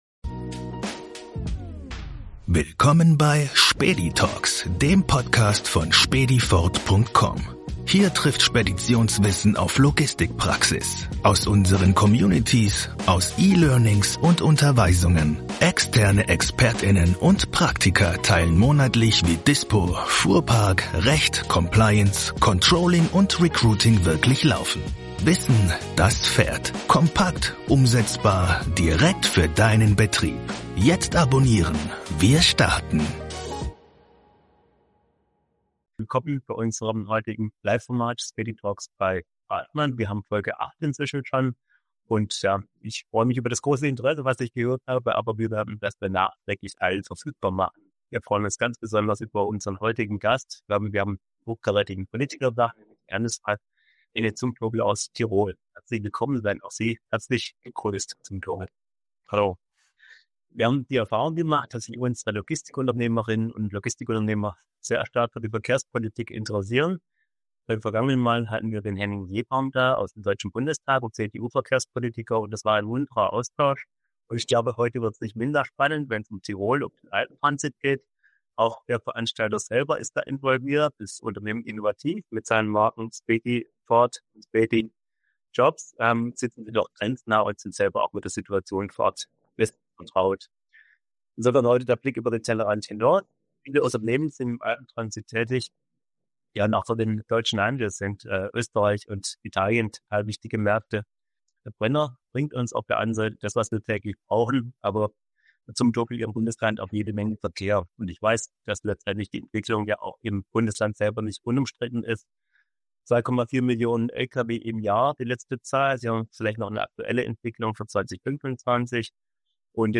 Als Host begrüße ich einen hochkarätigen Politiker aus Tirol, der uns Einblicke in die aktuelle Verkehrspolitik und die Herausforderungen des Alpentransits gibt. Wir diskutieren die Notwendigkeit eines digitalen Verkehrsmanagementsystems, um Staus zu vermeiden und die Effizienz im Transportwesen zu steigern. Dabei beleuchten wir auch die Rolle der Schiene im Vergleich zur Straße und die Potenziale von Elektro-Lkw.